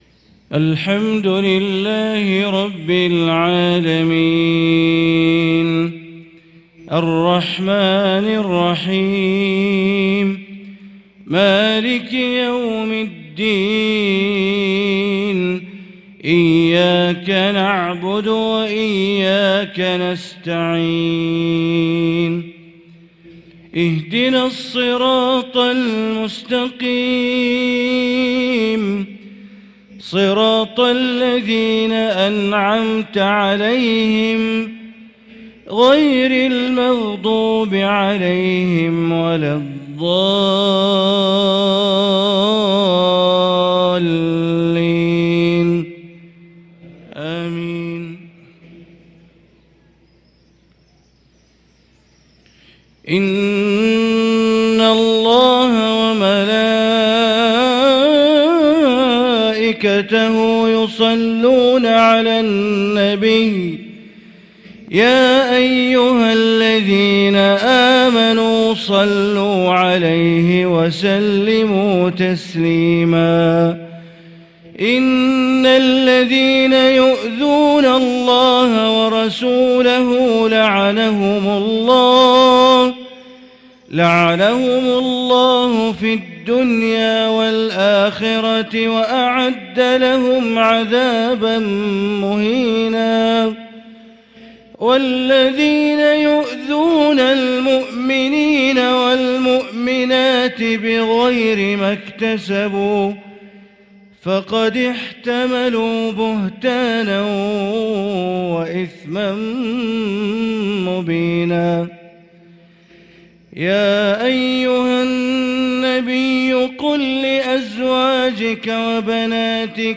صلاة الفجر للقارئ بندر بليلة 30 جمادي الآخر 1445 هـ
تِلَاوَات الْحَرَمَيْن .